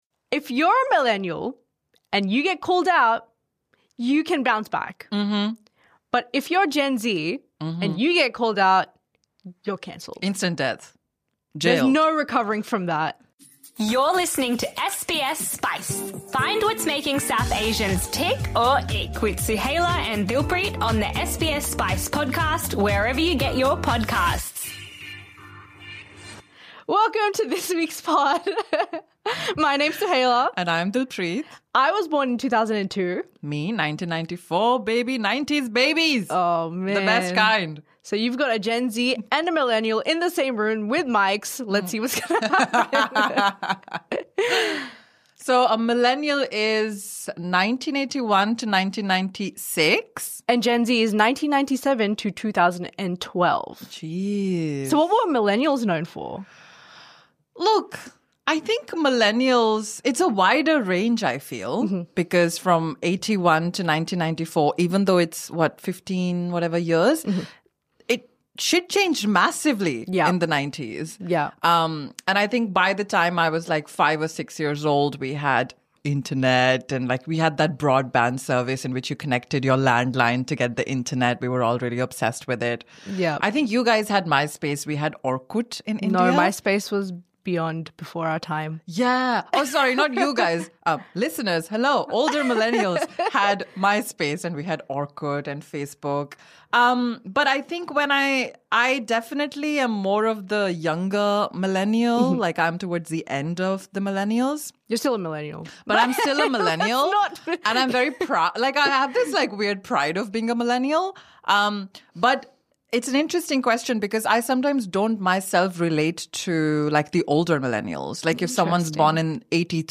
They dive into cancel culture, low-rise jeans, therapy-speak, and why Gen Alpha already scares them both. It’s fast, funny and a tiny bit existential.